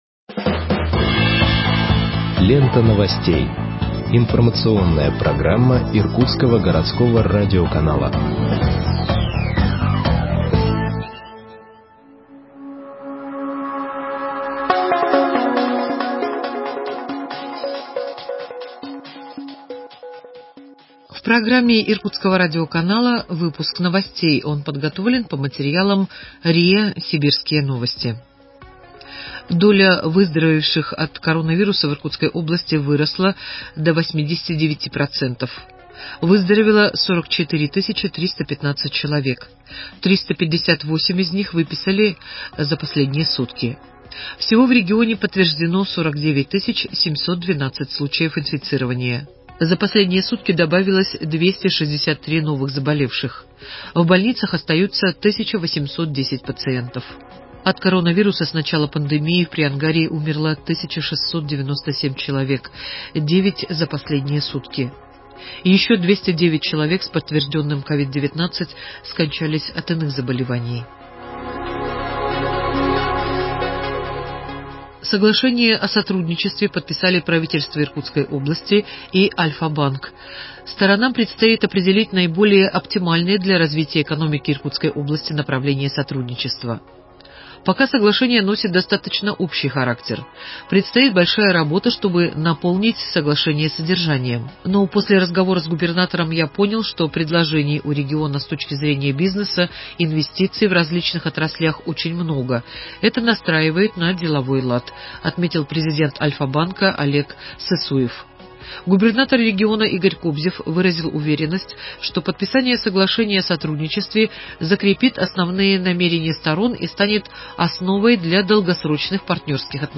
Выпуск новостей в подкастах газеты Иркутск от 03.02.2021 № 2